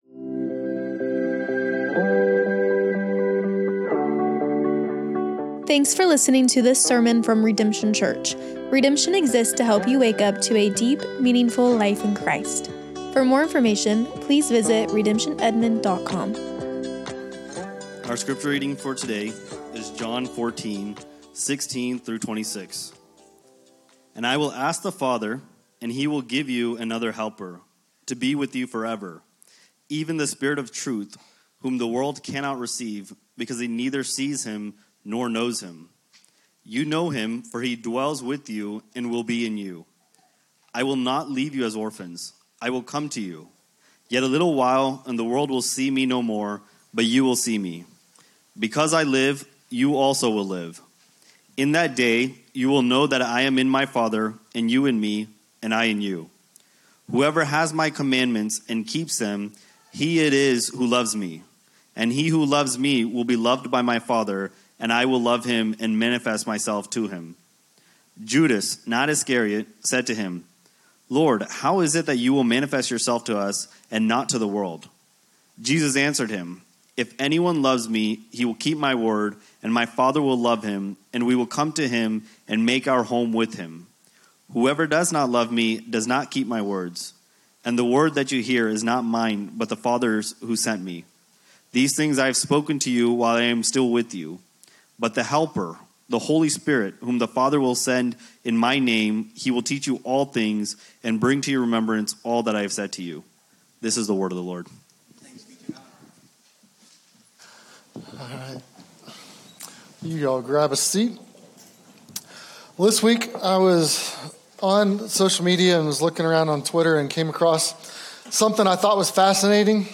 SERMONS - Redemption Church